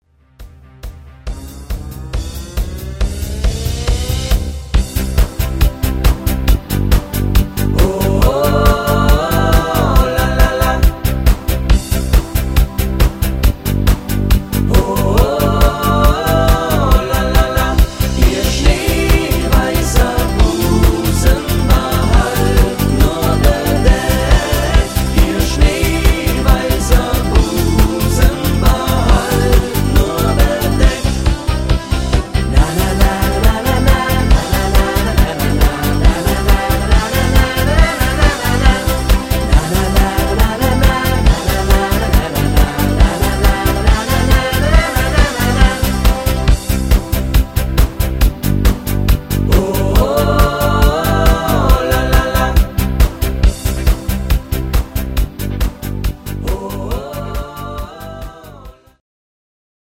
Partyversion